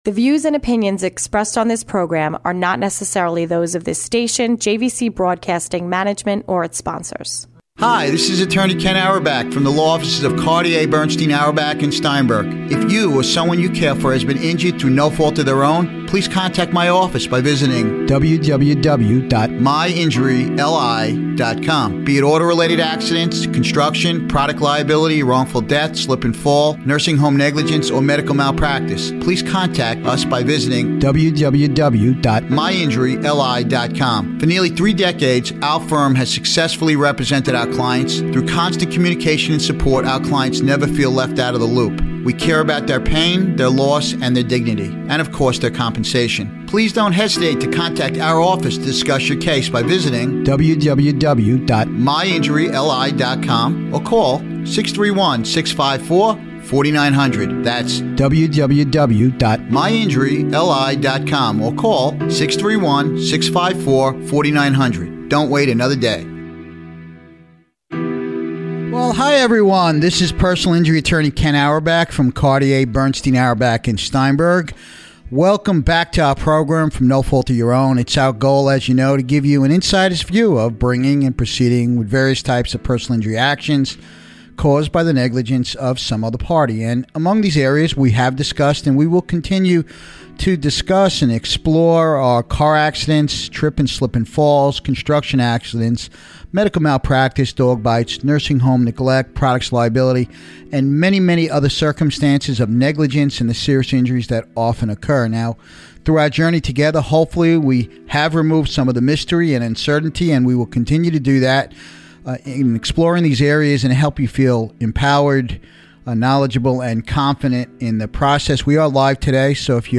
Guest Neuropsychologist